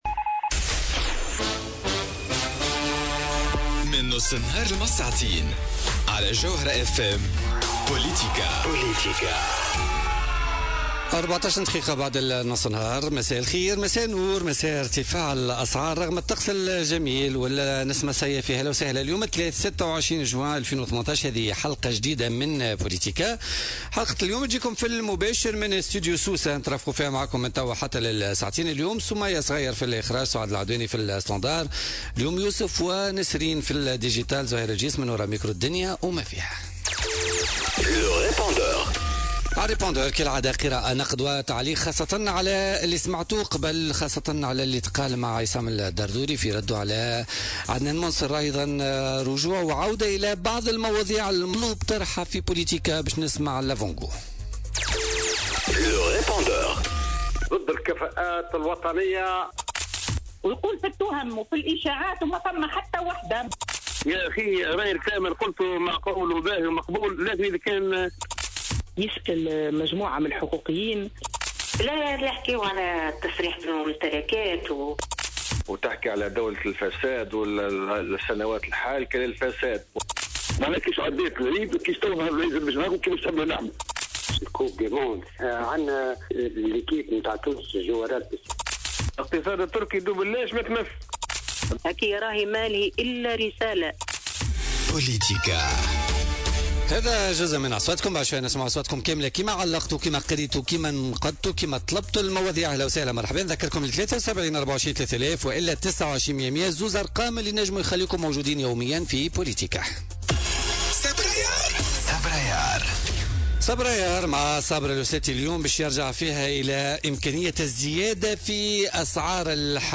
النائب المنجي الرحوي ضيف بوليتيكا